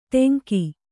♪ ṭeŋki